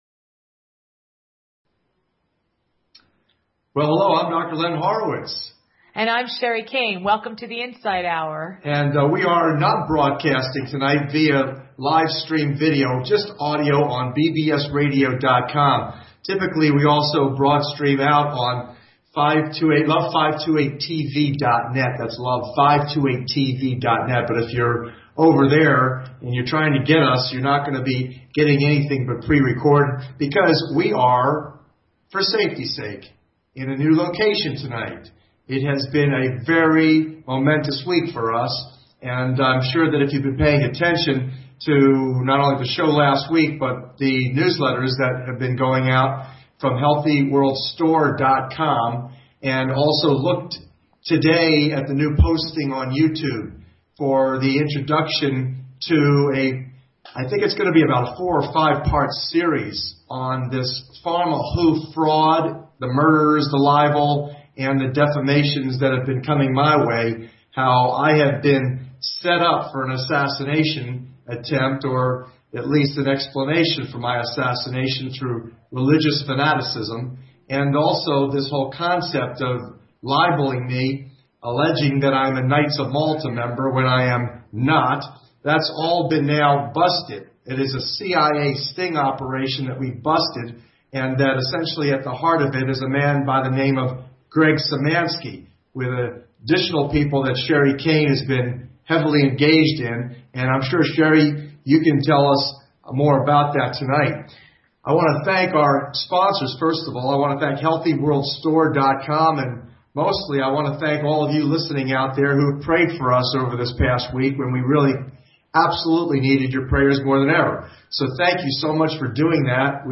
Talk Show Episode, Audio Podcast, The_Insight_Hour and Courtesy of BBS Radio on , show guests , about , categorized as